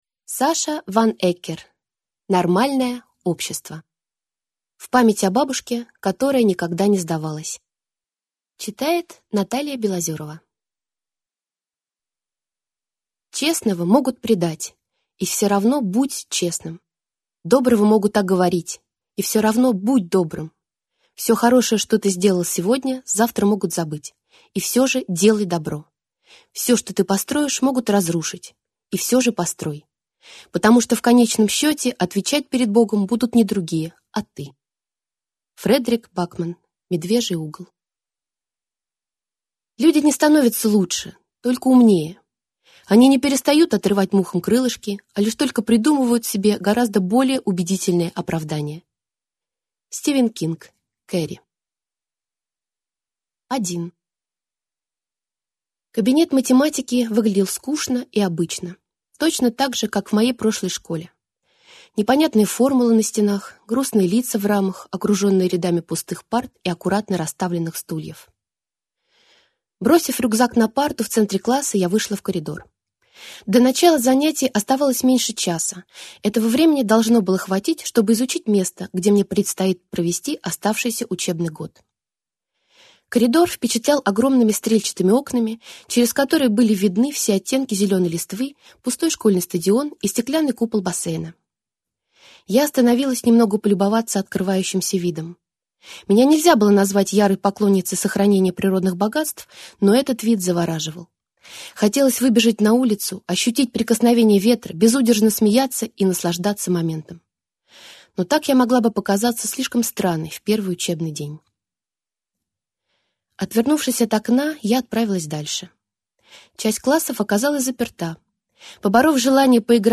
Аудиокнига Нормальное общество | Библиотека аудиокниг